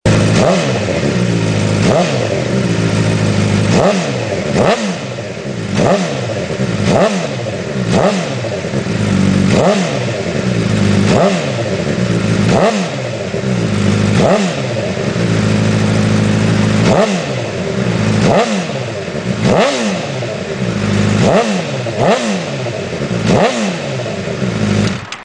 Fireblade 08-13 ohne db-Killer(2).mp3